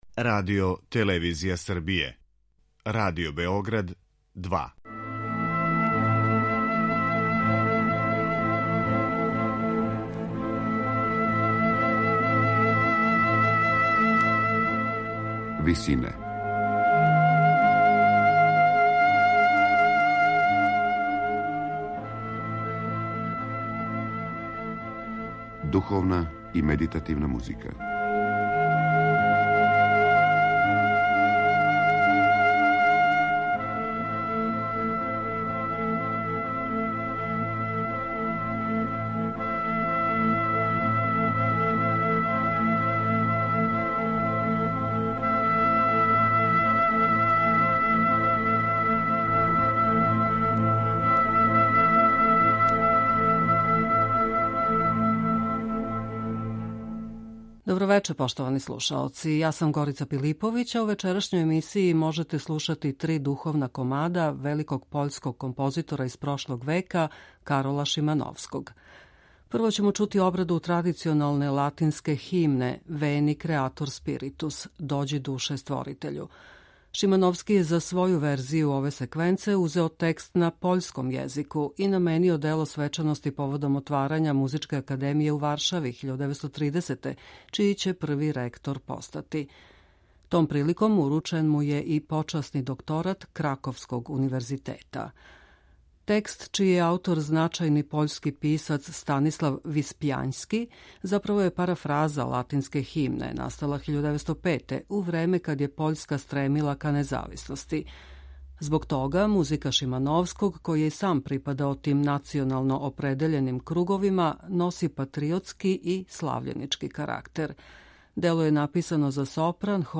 У вечерашњој емисији Висине можете слушати три духовне композиције великог пољског композитора из прошлог века Карола Шимановског.
медитативне и духовне композиције
Прво ћемо емитовати обраду традиционалне латинске химне Veni, creator spiritus - Дођи, душе, створитељу.